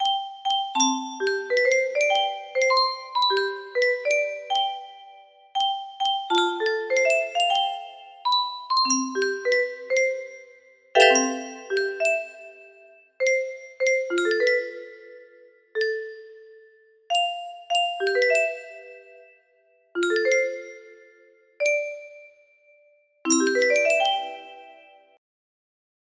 Unknown Artist - Untitled music box melody
Wow! It seems like this melody can be played offline on a 15 note paper strip music box!